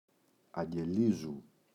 αγγελίζου [aŋge’lizu]: προσφέρω στους φτωχούς ελεημοσύνη. [αγγελ(ία) -ίζου].